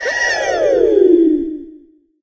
tara_kill_vo_02.ogg